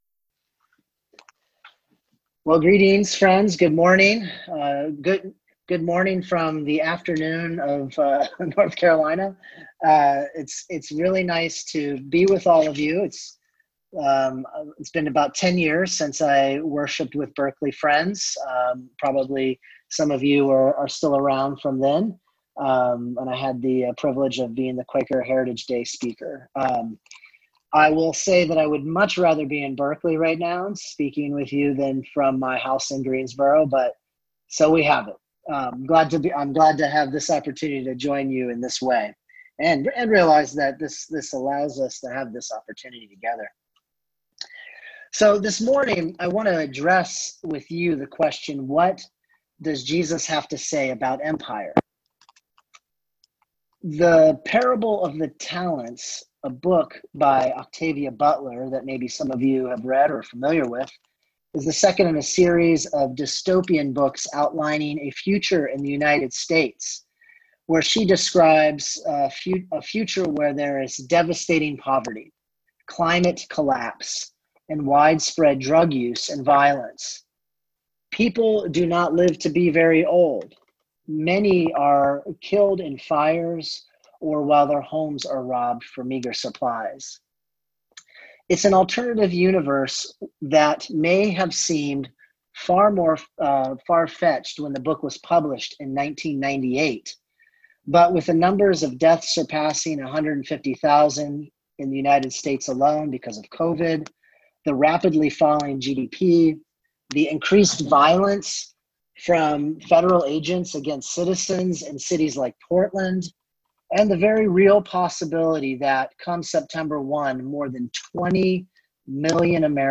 Listen to the most recent message from Sunday worship at Berkeley Friends Church, “Jesus Against Empire.”